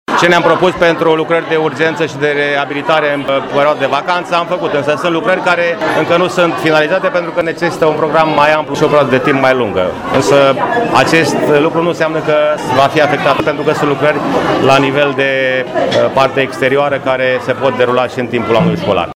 Primarul Braşovului, George Scripcaru: